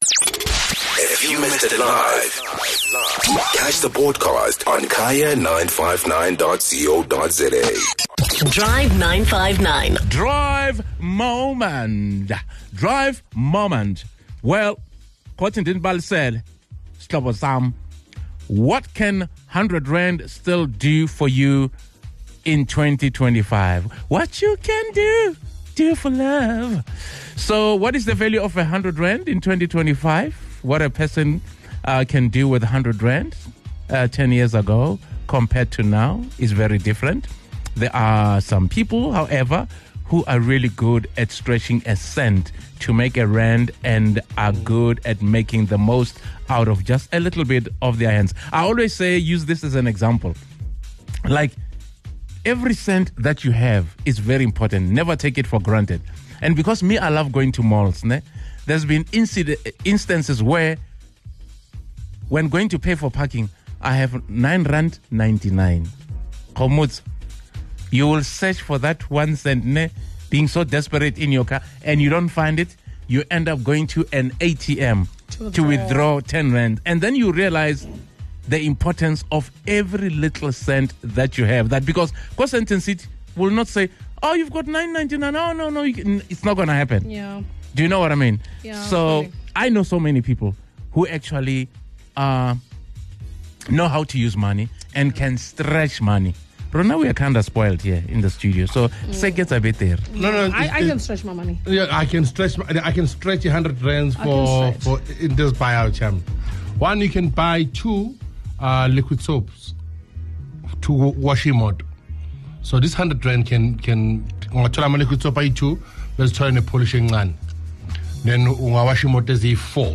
Take a listen to what some of our Drive listeners said about how they can stretch R100.